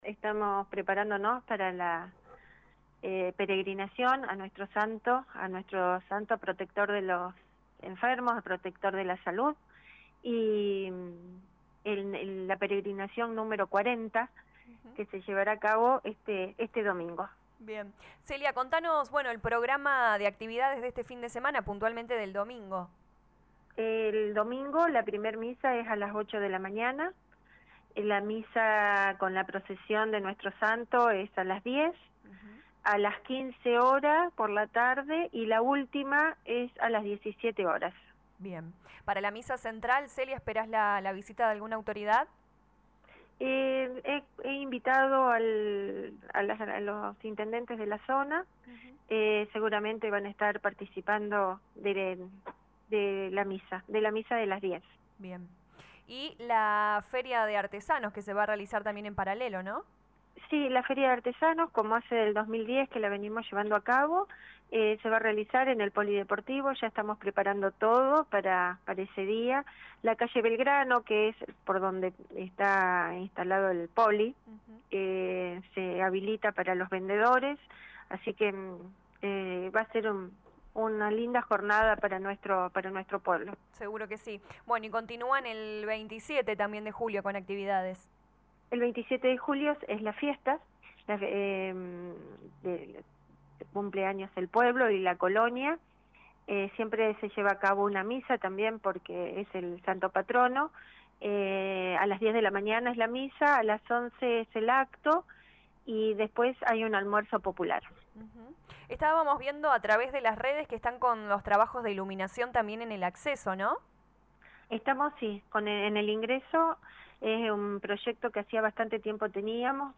LA RADIO 102.9 FM habló con la intendente Celia Giorgis quién informó que se realizarán misas los días 23 y 27 del corriente mes y además feria de artesanos, santería y servicio de buffet.